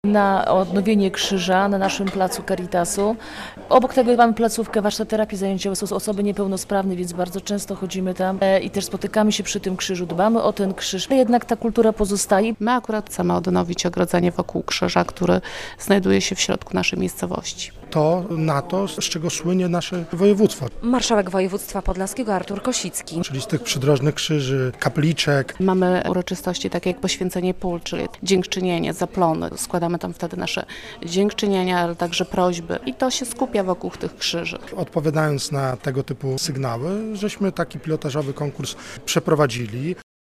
- mówił przy okazji podpisania umów marszałek.